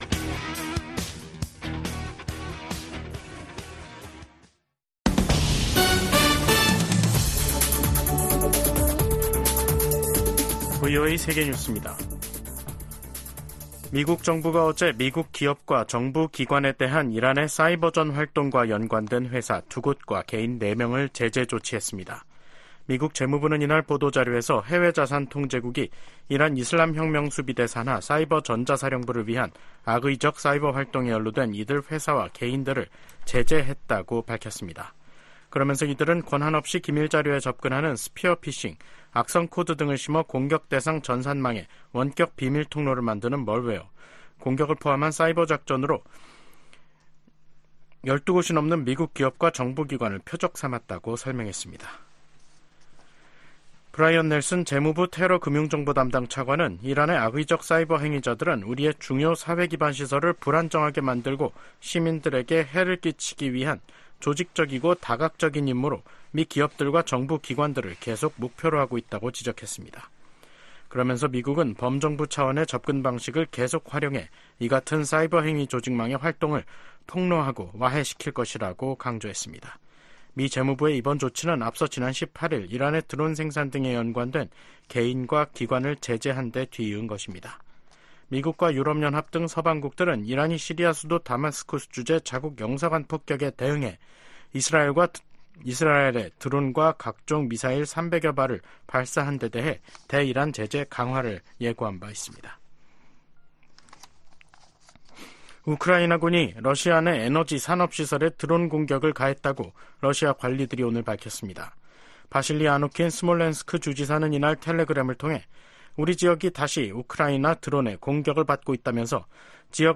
VOA 한국어 간판 뉴스 프로그램 '뉴스 투데이', 2024년 4월 24일 3부 방송입니다. 미 국무부는 북한의 핵반격훈련 주장에 무책임한 행동을 중단하고 진지한 외교에 나서라고 촉구했습니다. 미 국방부는 역내 안보를 위해 한국, 일본과 긴밀히 협의하고 있다고 밝혔습니다. 북한의 김여정 노동당 부부장은 올들어 이뤄진 미한 연합훈련 모두를 비난하며 핵 무력을 계속 비축하겠다고 밝혔습니다.